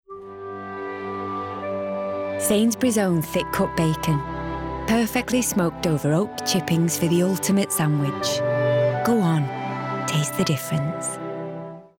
Northern
Commercial, Smooth, Warm, Honest, Friendly